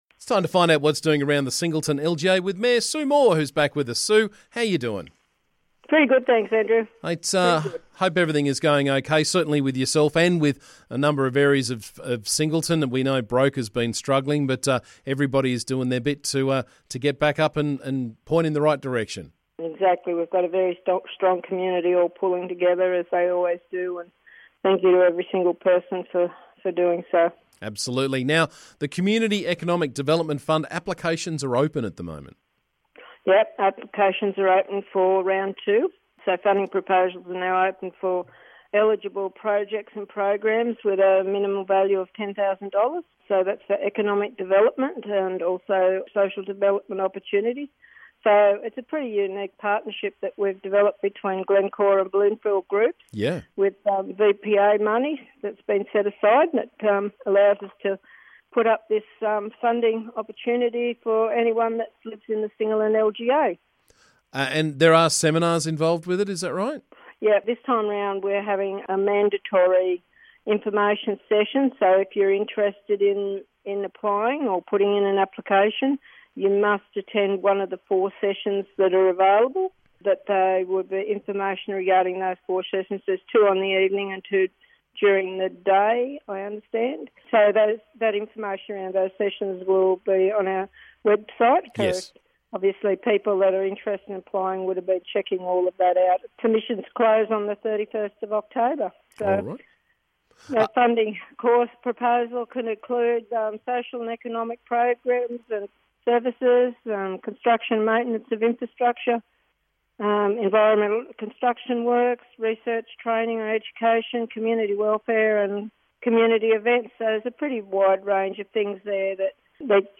We caught up with Singleton Council Mayor Sue Moore to find out what is doing around the district.